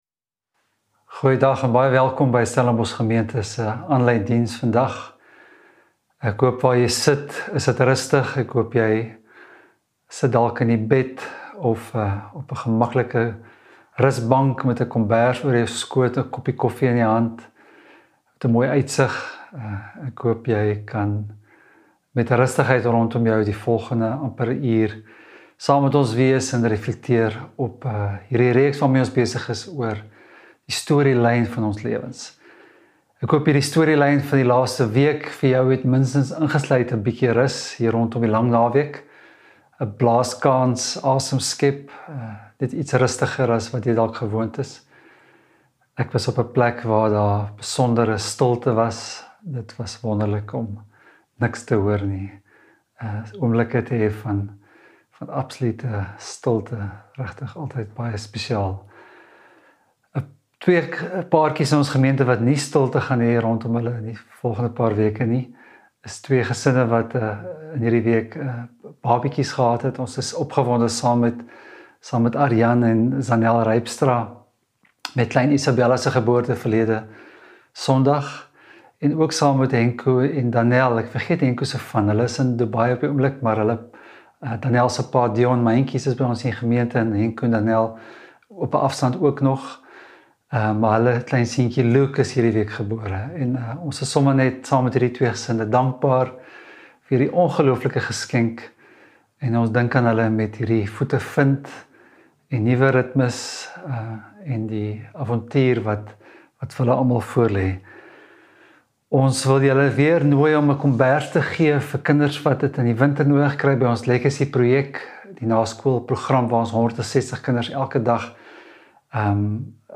Preke